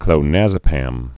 (klō-năzə-păm)